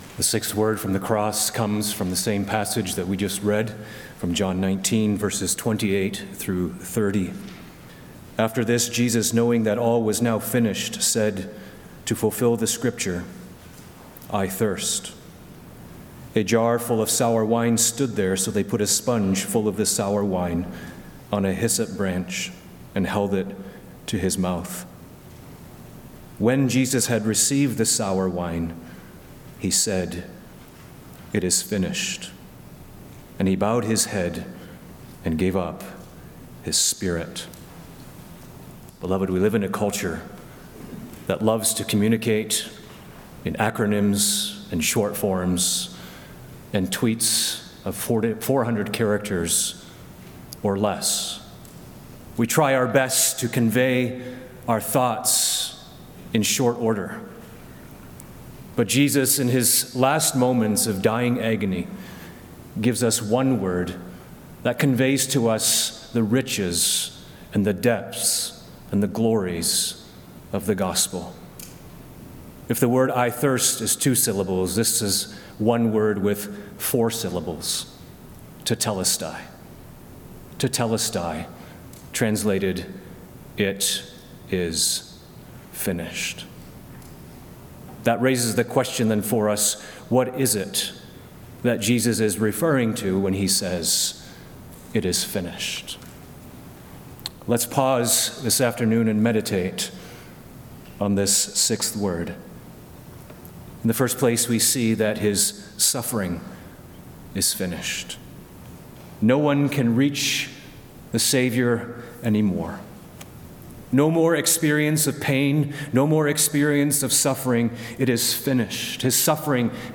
Community Good Friday 2025 6th Word